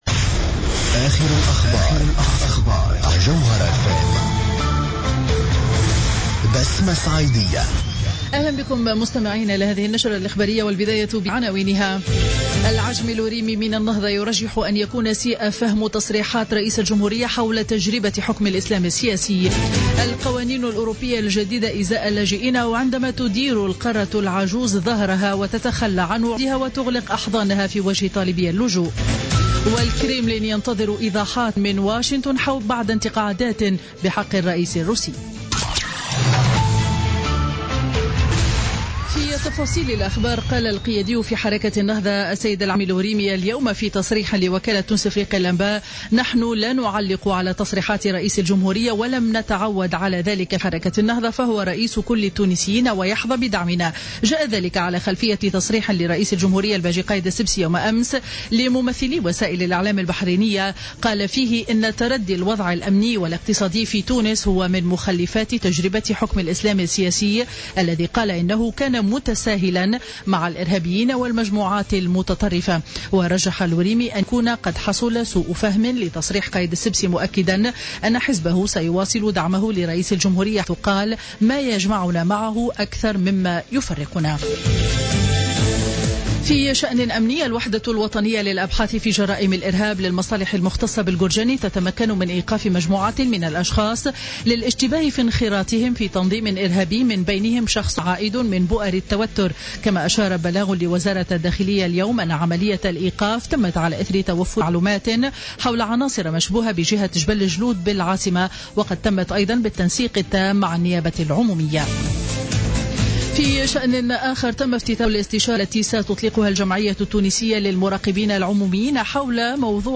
نشرة أخبار منتصف النهار ليوم الجمعة 29 جانفي 2016